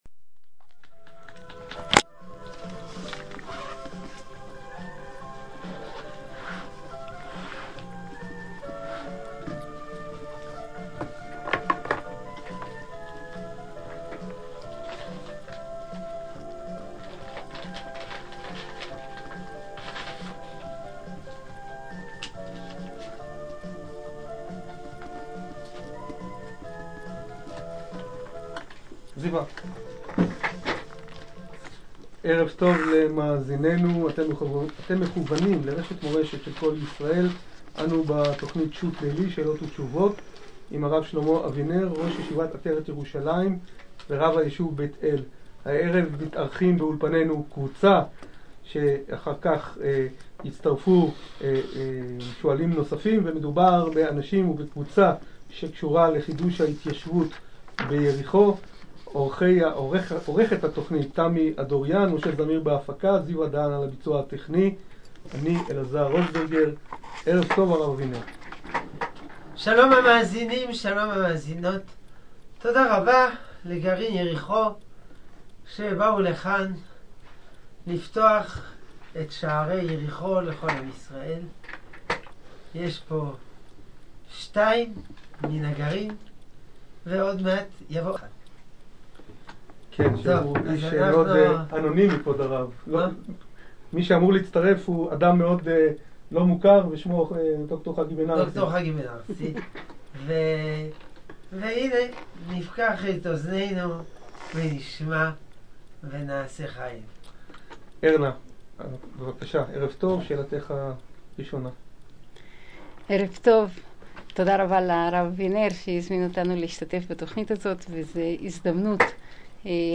שאלות ותשובות על יריחו - תוכנית ברשת מורשת
Jericho_QandA.mp3